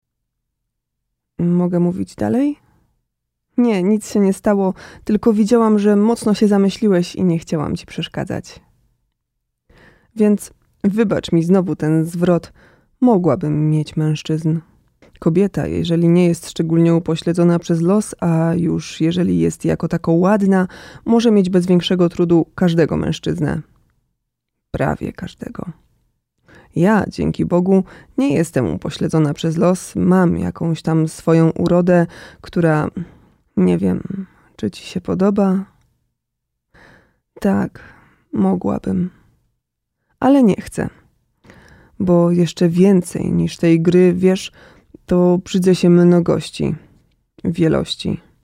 Znani i lubiani Mężczyzna 50 lat +
Ciepły i niski głos znany z reklam radiowych i telewizyjnych.
Nagranie lektorskie